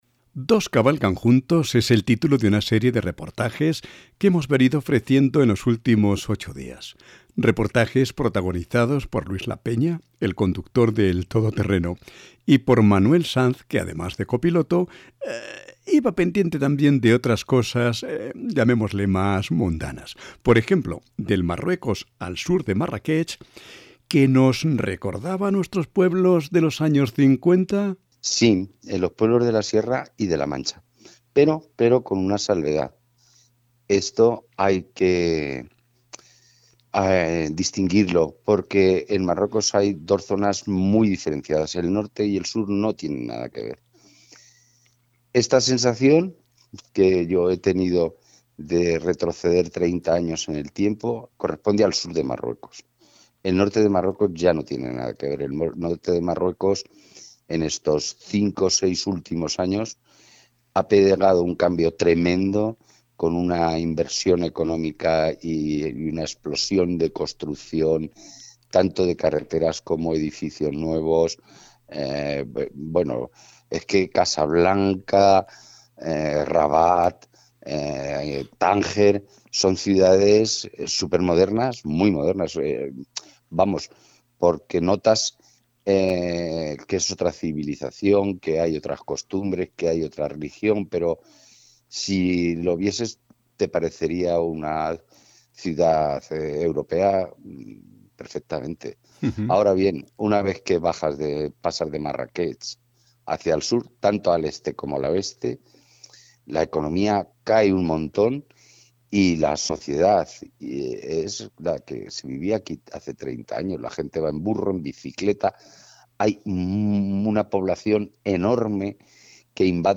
Pódcast. Dos Cabalgan Juntos.